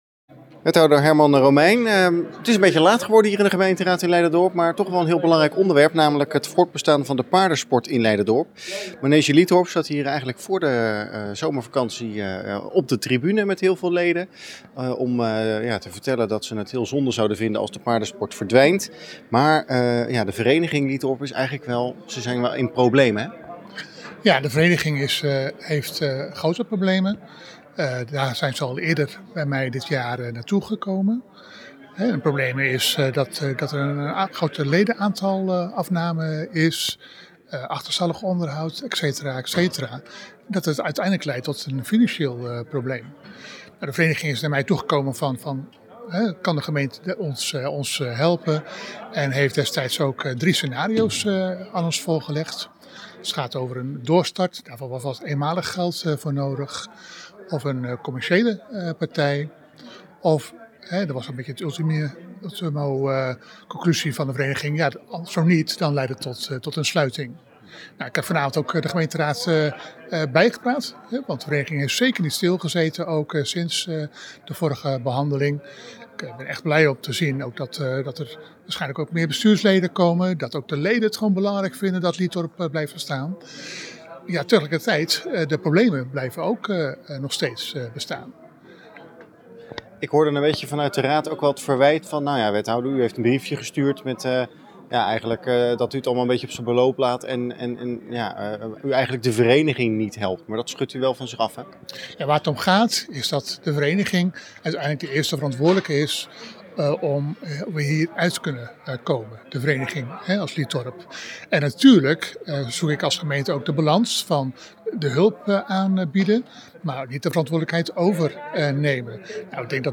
Wethouder Herman Romeijn over Liethorp.